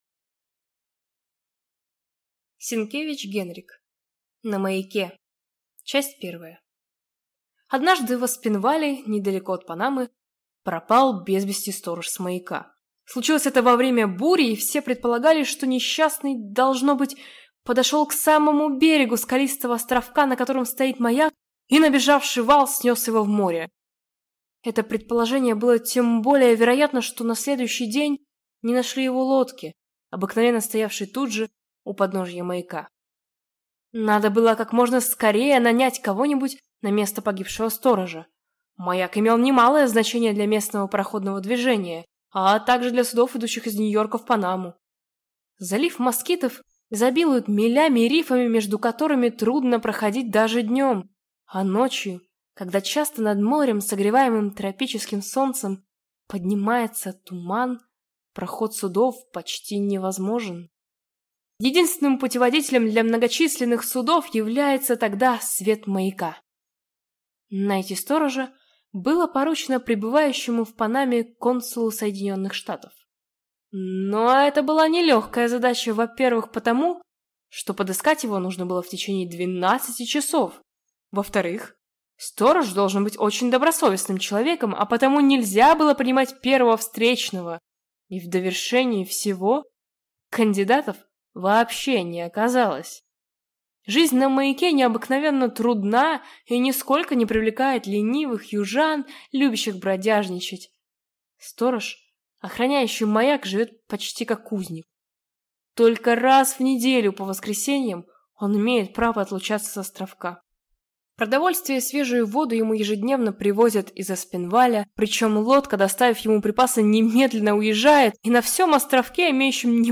Аудиокнига На маяке | Библиотека аудиокниг